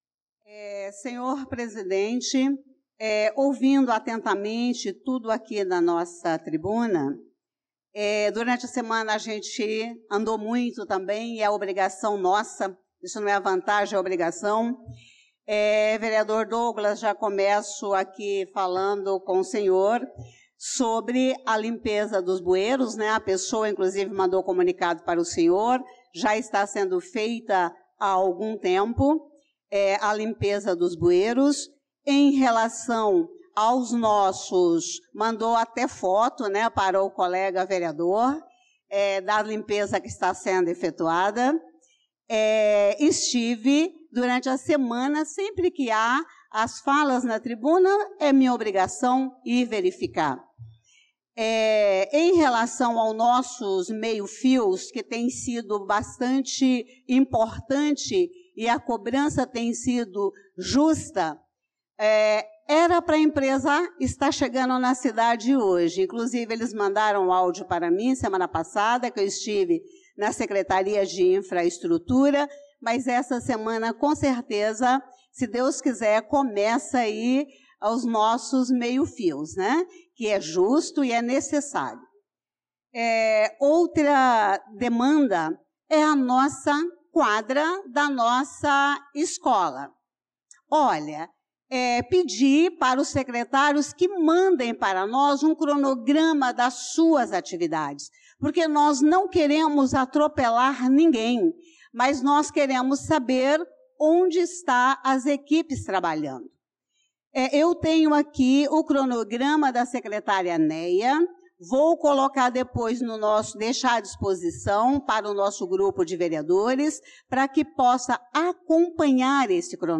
Pronunciamento da vereadora Elisa Gomes na Sessão Ordinária do dia 25/02/2025